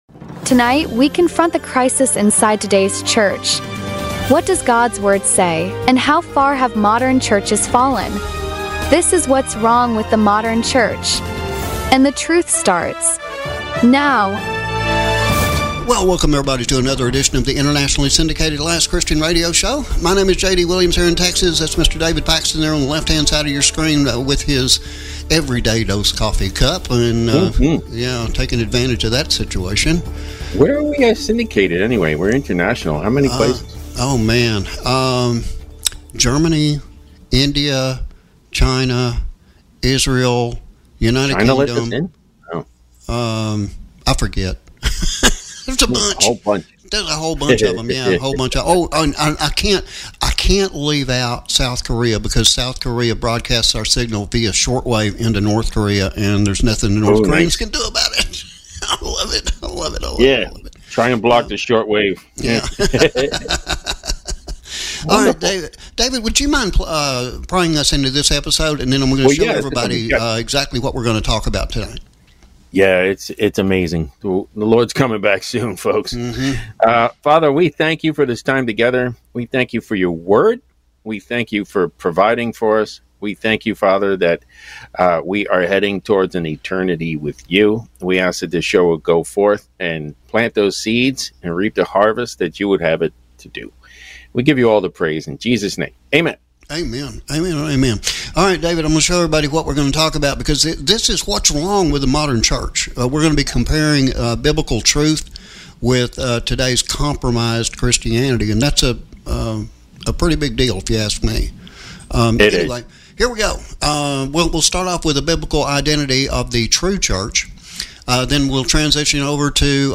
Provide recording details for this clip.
Through a bold, unfiltered discussion rooted in Scripture, we reveal why the early Church turned the world upside down… and why the modern church is struggling to stand.